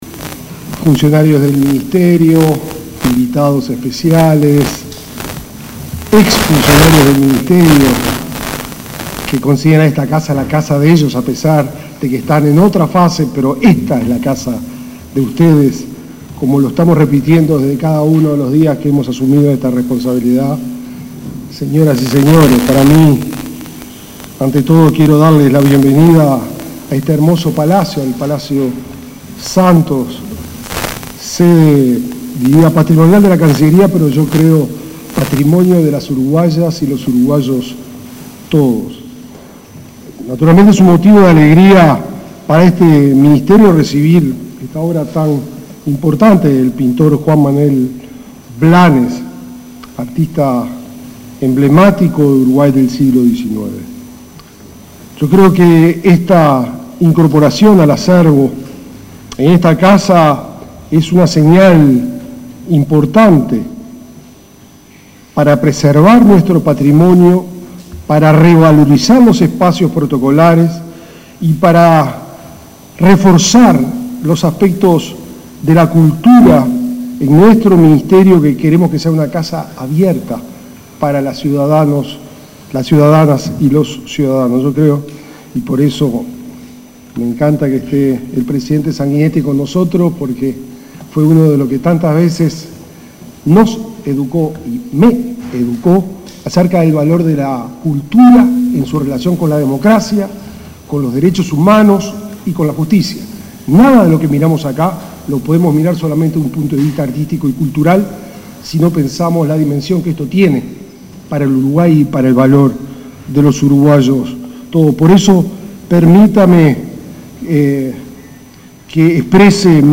Palabras de autoridades en acto en Cancillería